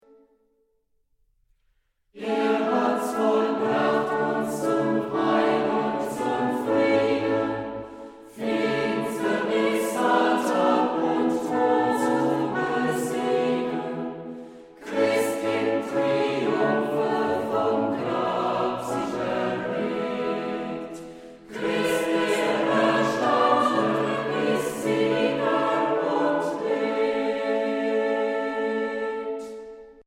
Instrumentalsätze für variable Besetzungen